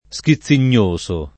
vai all'elenco alfabetico delle voci ingrandisci il carattere 100% rimpicciolisci il carattere stampa invia tramite posta elettronica codividi su Facebook schizzinoso [ S ki ZZ in 1S o ] (pop. schizzignoso [ S ki ZZ in’n’ 1S o ]) agg.